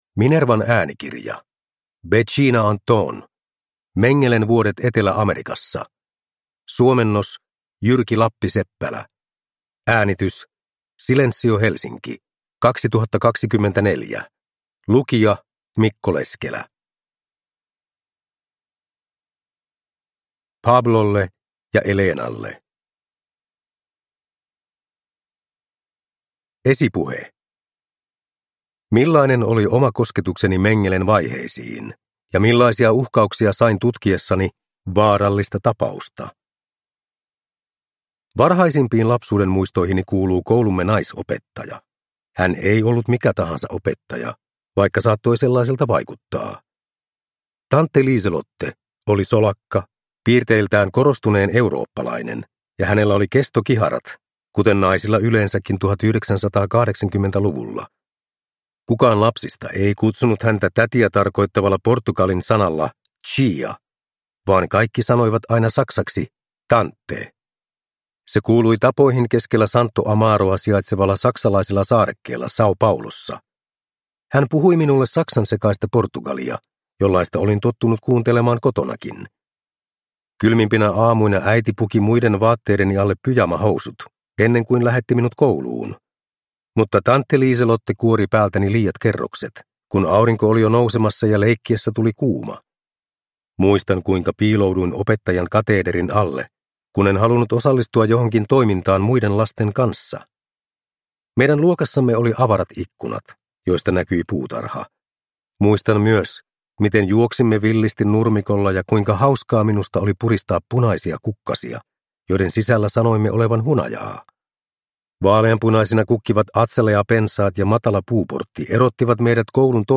Mengelen vuodet Etelä-Amerikassa – Ljudbok